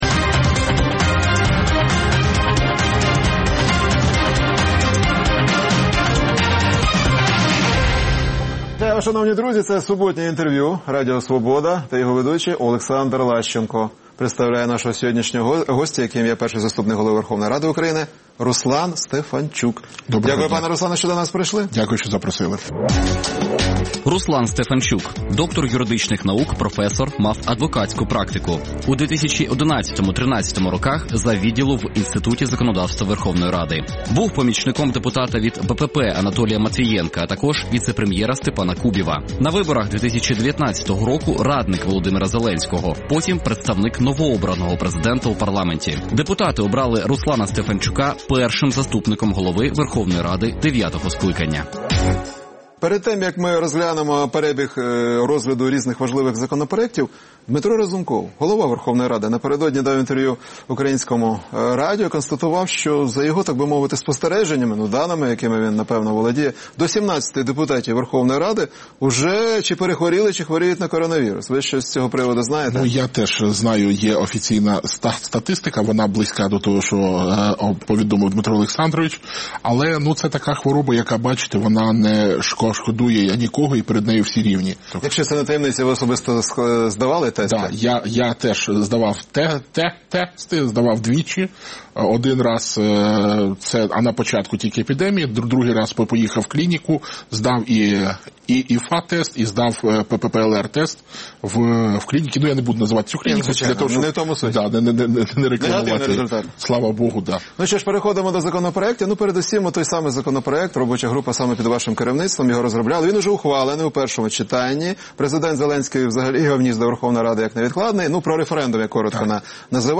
Суботнє інтерв’ю | Руслан Стефанчук перший заступник голови Верховної Ради
Суботнє інтвер’ю - розмова про актуальні проблеми тижня. Гість відповідає, в першу чергу, на запитання друзів Радіо Свобода у Фейсбуці